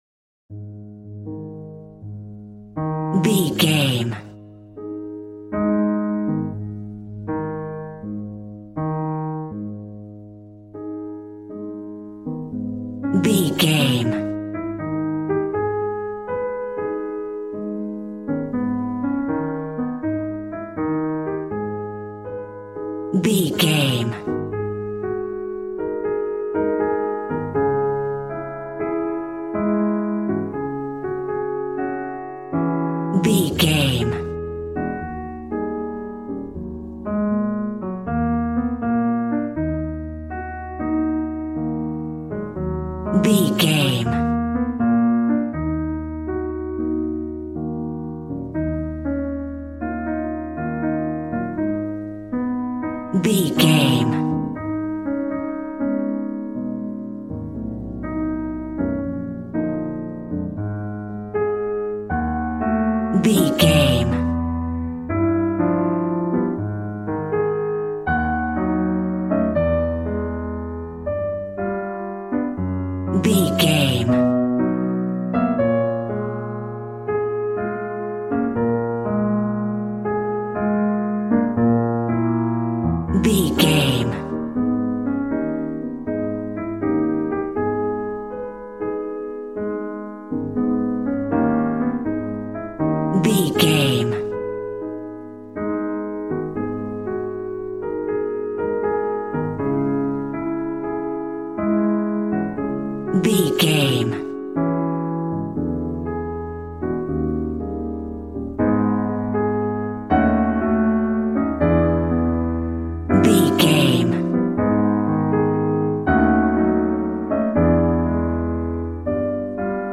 Smooth jazz piano mixed with jazz bass and cool jazz drums.,
Aeolian/Minor
A♭
drums